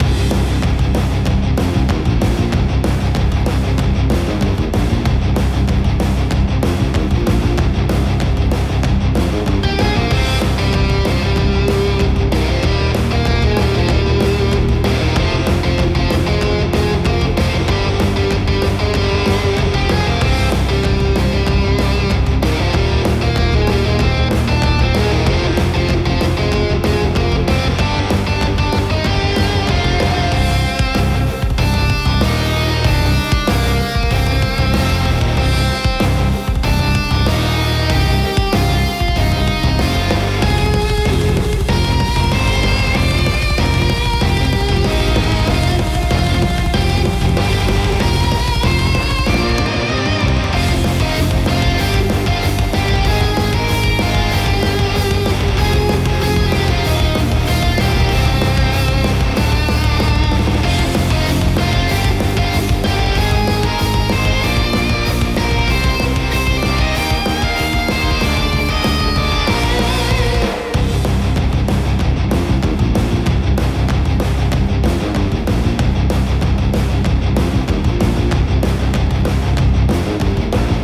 Music for battle theme.